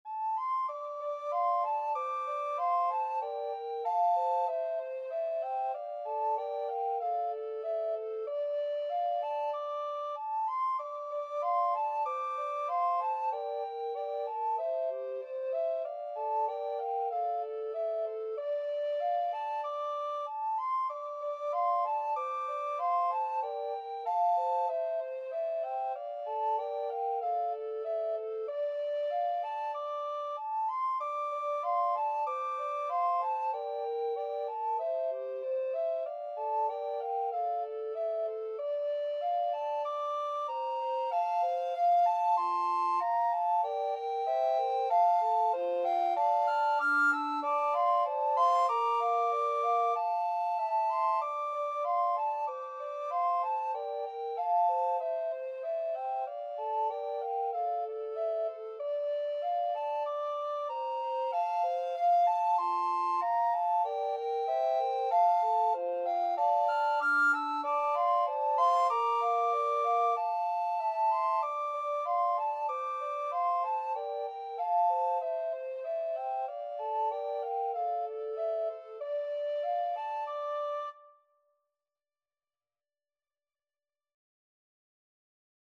Free Sheet music for Recorder Trio
Soprano RecorderAlto RecorderTenor Recorder
4/4 (View more 4/4 Music)
= 95 Moderato
D minor (Sounding Pitch) (View more D minor Music for Recorder Trio )
Traditional (View more Traditional Recorder Trio Music)